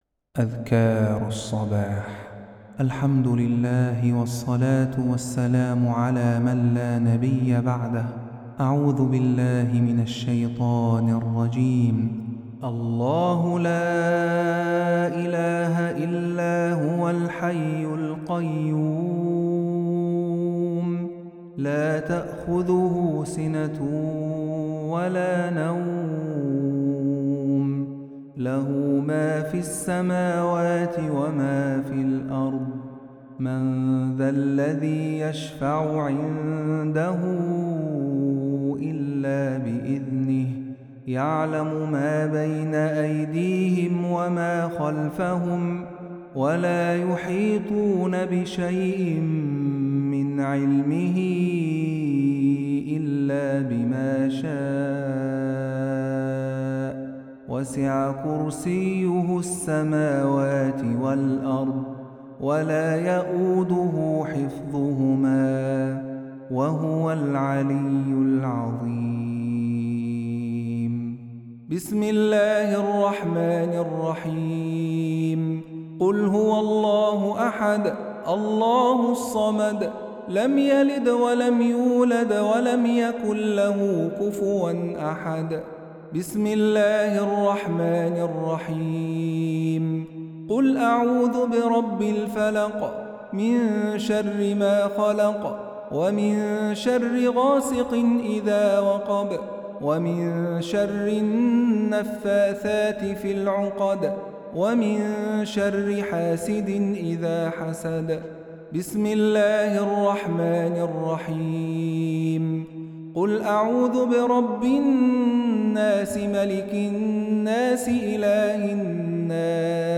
تلاوة مريحة لأذكار الصباح